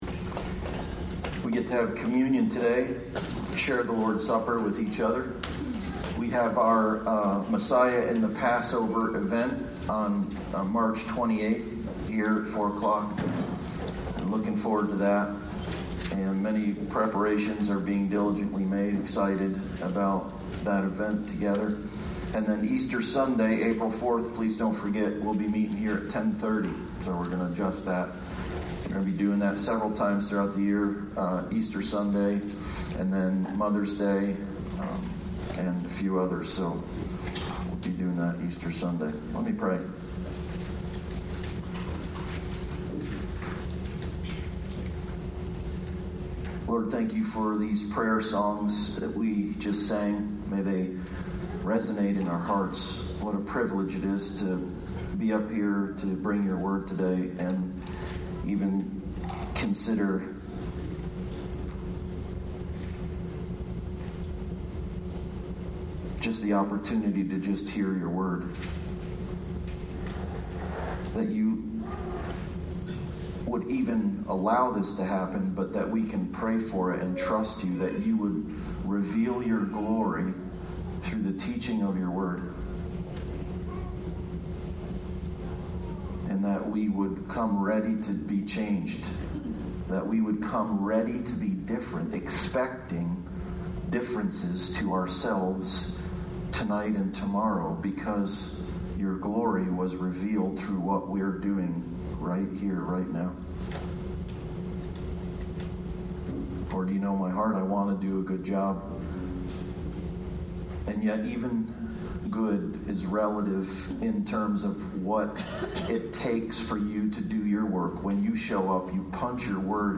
Snapshots of Jesus Passage: Luke 18:31-34 Service Type: Sunday Service « Snapshots of Jesus